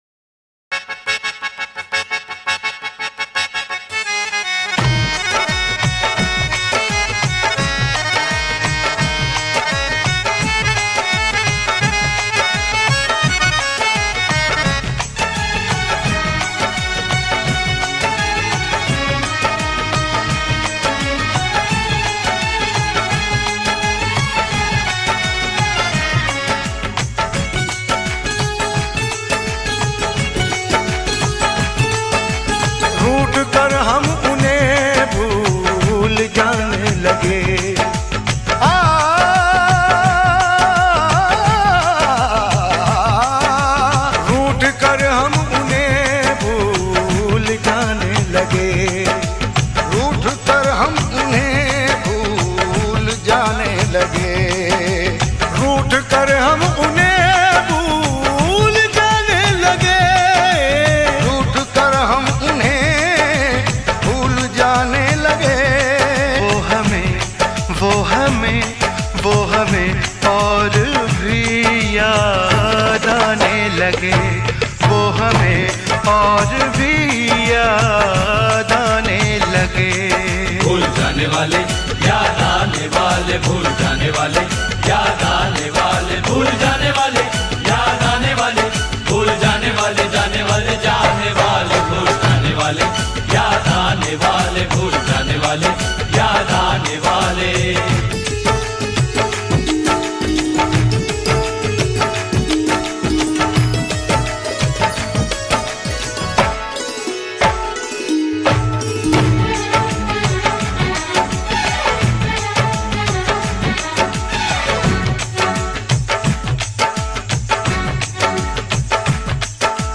Bollywood old songs
90s Hindi songs
sad song